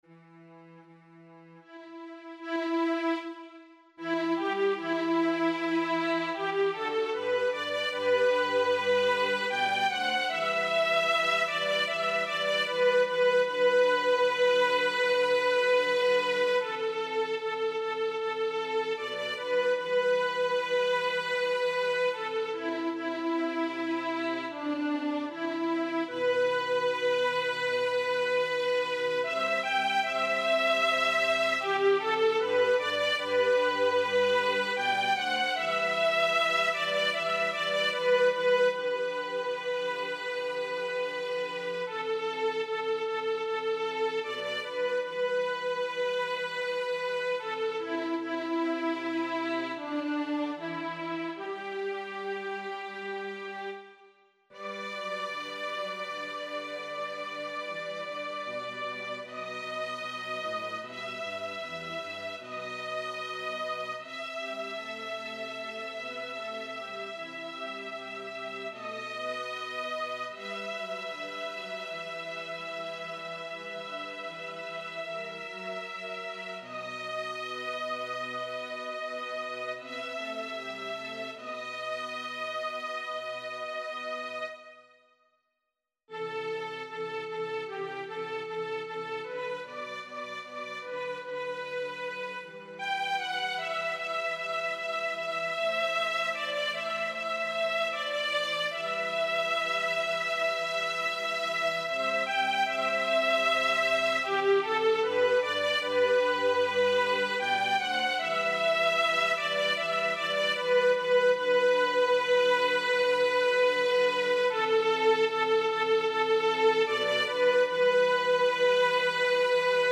男声合唱曲です。
パートごとに音取りができます。
男フェス2017用音取り